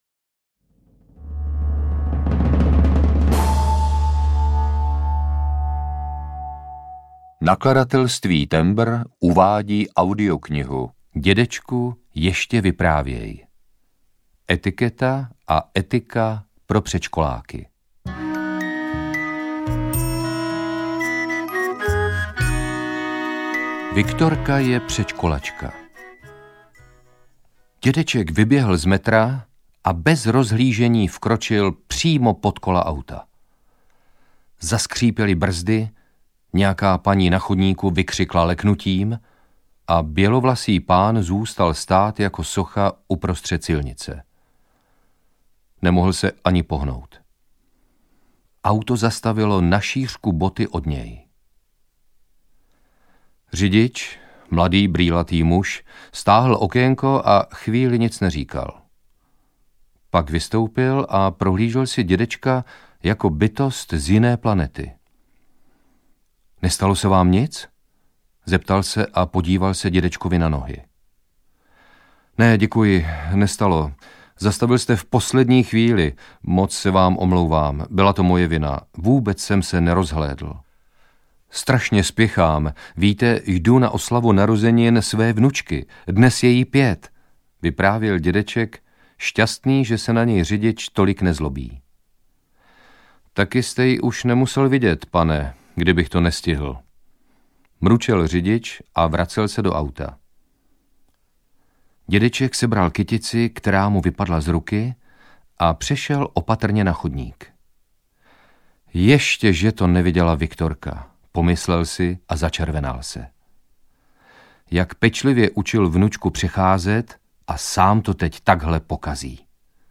Dědečku, ještě vyprávěj audiokniha
Ukázka z knihy
• InterpretLadislav Špaček